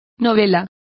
Complete with pronunciation of the translation of novels.